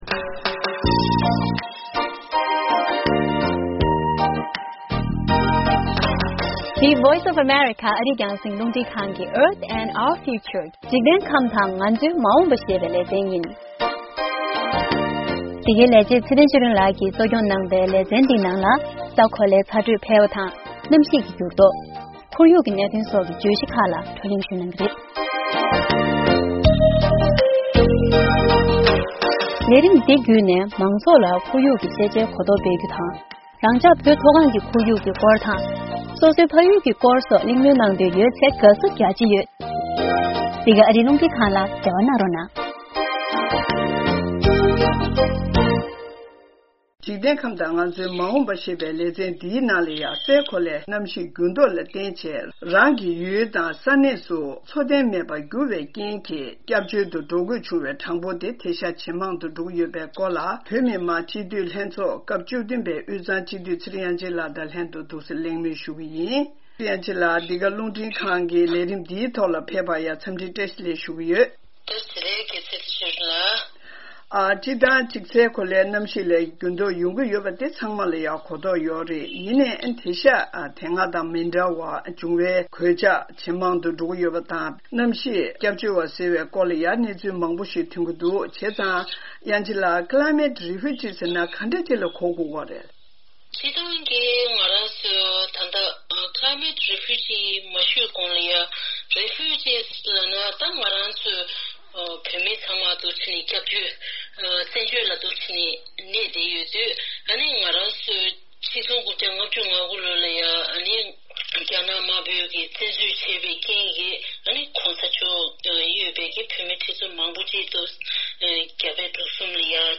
སའི་གོ་ལའི་གནམ་གཤིས་འགྱུར་ལྡོག་ལ་བརྟེན་ནས་མི་མང་པོ་རང་གནས་སུ་འཚོ་རྟེན་མེད་པར་གྱུར་ཙང་ལོ་ལྟར་ས་གནས་གཞན་ལ་སྐྱབས་བཅོལ་དུ་འགྲོ་དགོས་བྱུང་བའི་མི་གྲངས་ཇེ་མང་དུ་སོང་སྟེ་༢༠༥༠ལོར་ཁོར་ཡུག་སྐྱབས་བཅོལ་བ་ཐེར་འབུམ་༡.༢ ལ་སླེབས་སྲིད་པའི་སྐོར་ས་ཁམས་རིག་པ་བ་དབུས་གཙང་སྤྱི་འཐུས་ཚེ་རིང་དབྱངས་ཅན་དང་གླེང་མོལ་ཞུས་ཡོད།